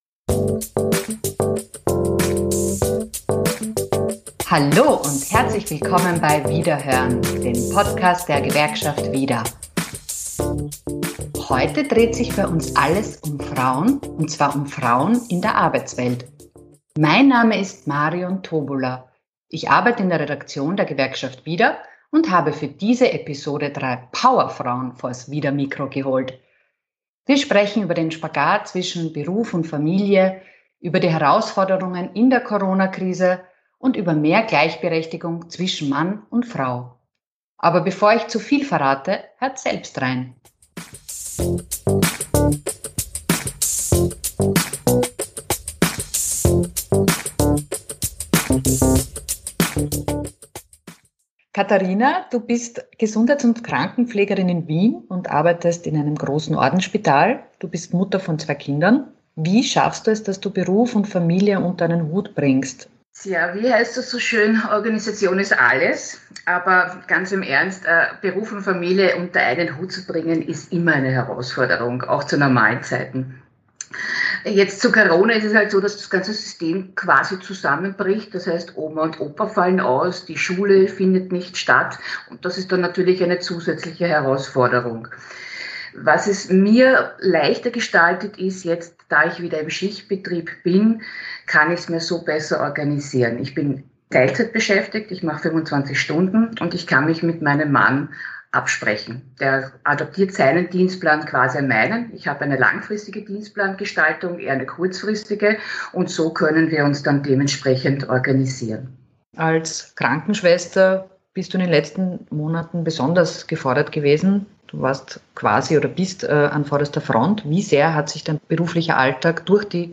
Heldinnen in der Arbeitswelt: drei Power-Frauen im Gespräch ~ vidaHören Podcast
Drei Power-Frauen im Gespräch mit „vidaHören“ über den Spagat zwischen Beruf und Familie, über die Herausforderungen in der Corona-Krise und über mehr Gleichberechtigung zwischen Mann und Frau.